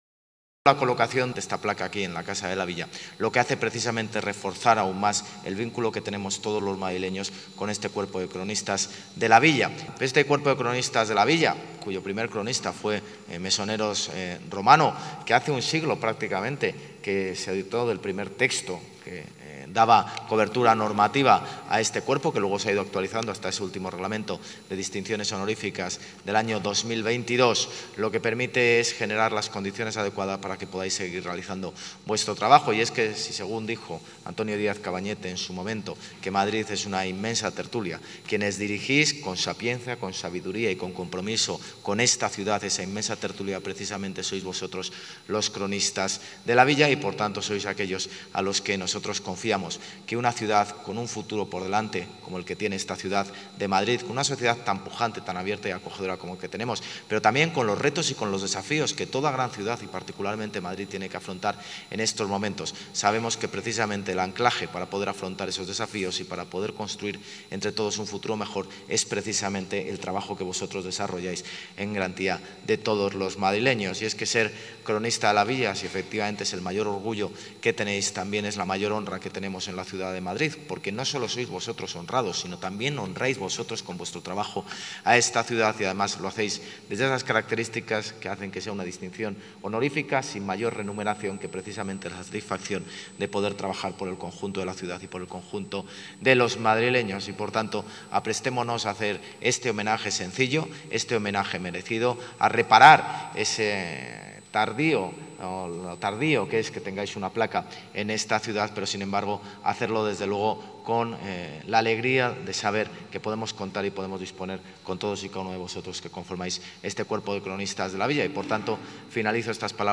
El alcalde de Madrid, José Luis Martínez-Almeida, junto a la vicealcaldesa y portavoz de Seguridad y Emergencias, Inma Sanz, y la delegada de Cultura, Turismo y Deporte, Marta Rivera de la Cruz, ha presentado hoy en la Casa de la Villa la placa de los Cronistas de la Villa, homenaje con el que el Ayuntamiento honra el legado de este colectivo y a todos los que seguirán dedicándose de forma altruista y a través de su actividad profesional a temas relacionados con la ciudad de Madrid.